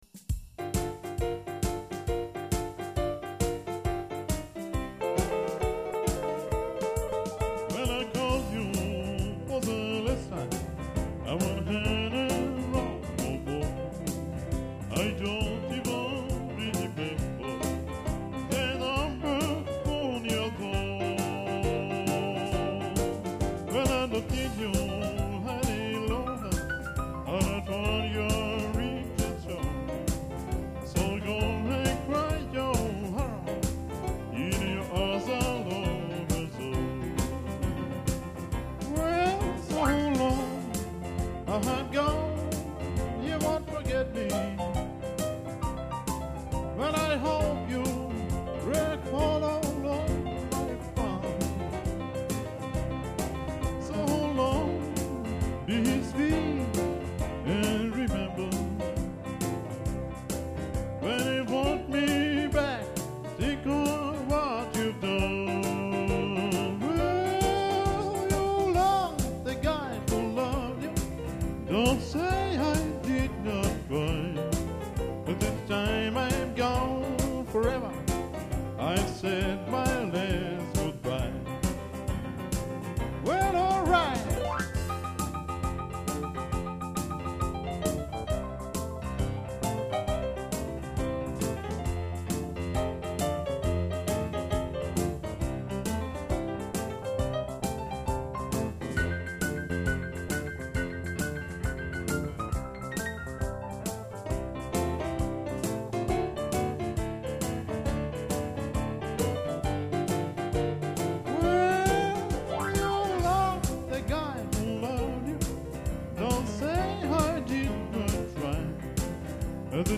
Гыыы! Ничего так, живенько. :-)
Слушал с удовольствием, порадовали клавишные.